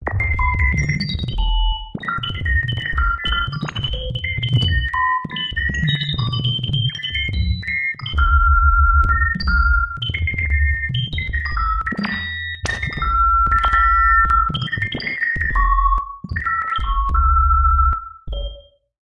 电气效果 2 " 261102C
描述：同步高、低振荡器声音的极端序列。
Tag: 电子 混响 传播